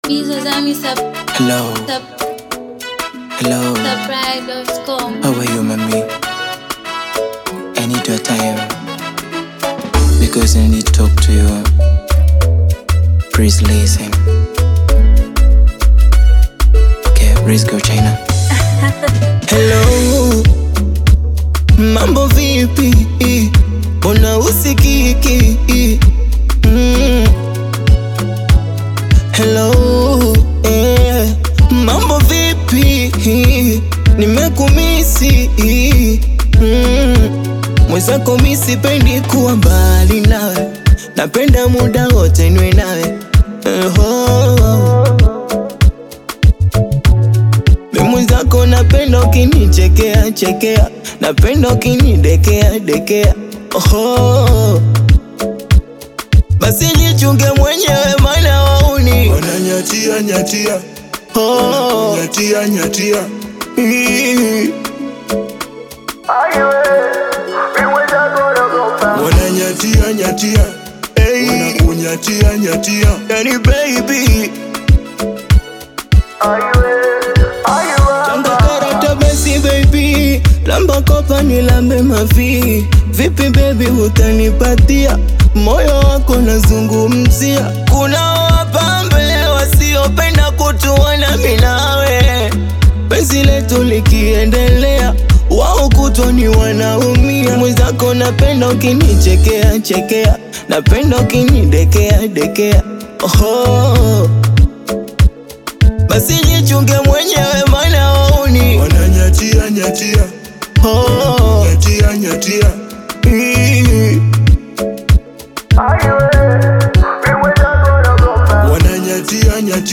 bongo flavour • Bongo Fleva